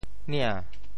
“岭”字用潮州话怎么说？
岭（嶺） 部首拼音 部首 山 总笔划 8 部外笔划 5 普通话 lǐng líng 潮州发音 潮州 nian2 白 中文解释 岭 <名> (形声。